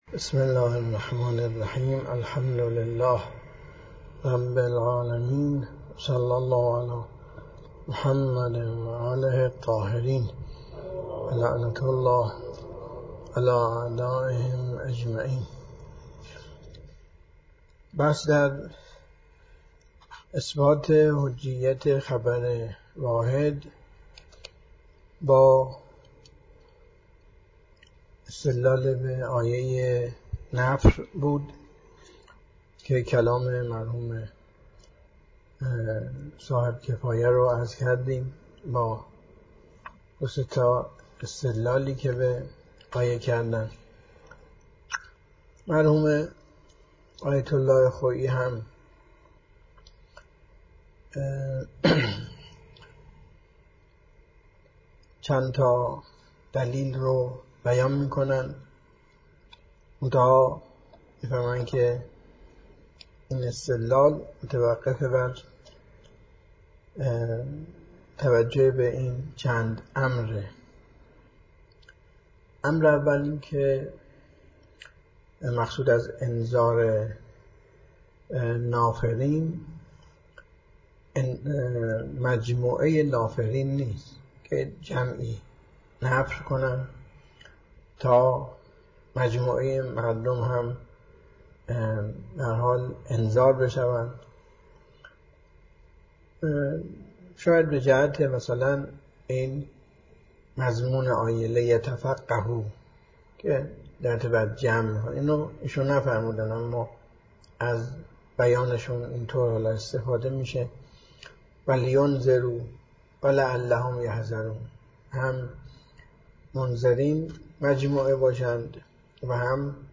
درس خارج